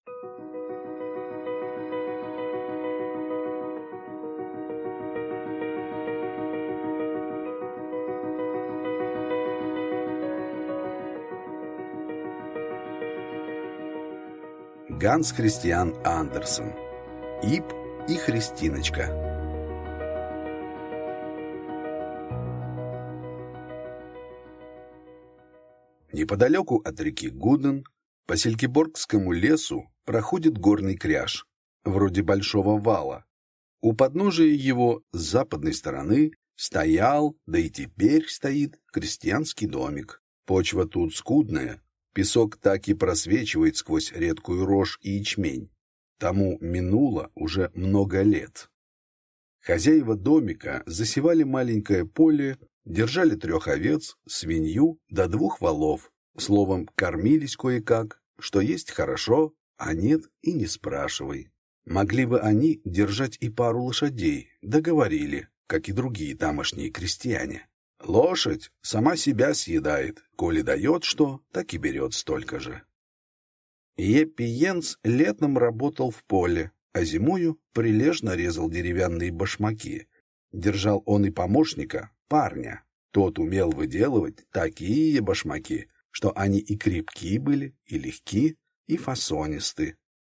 Аудиокнига Иб и Христиночка